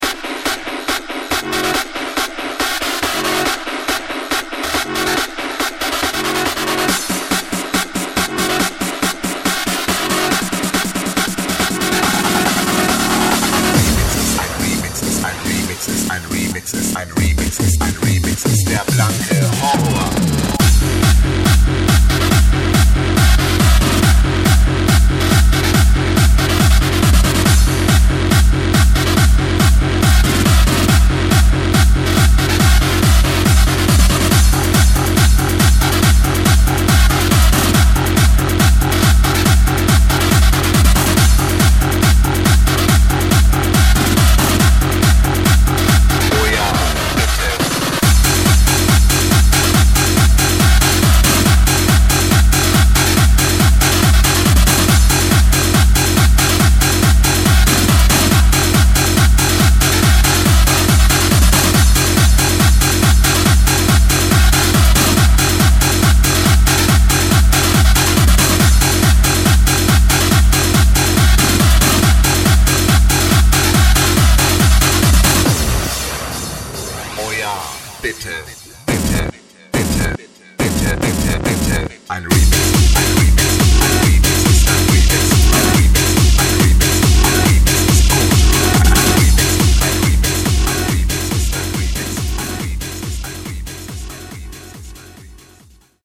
Hard-Trance, Hardstyle, Oldschool-Tech-House